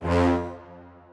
strings4_3.ogg